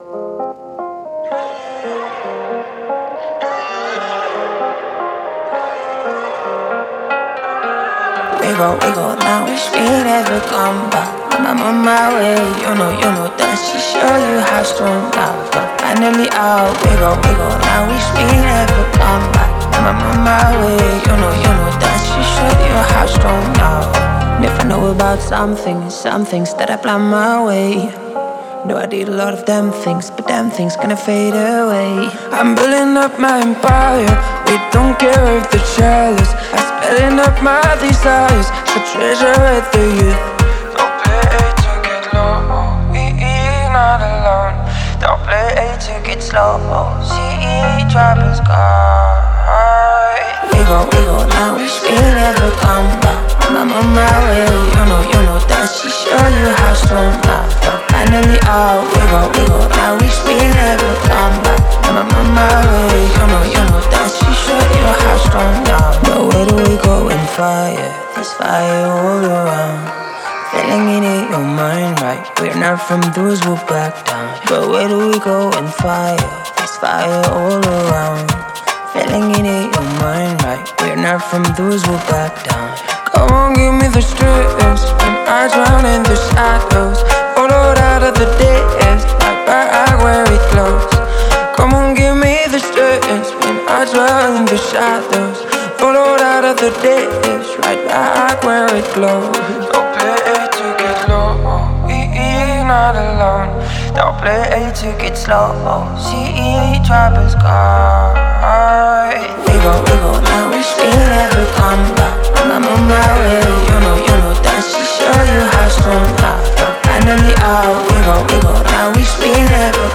мелодичные гитары, ритмичные ударные и характерный вокал
создавая атмосферу лёгкости и радости.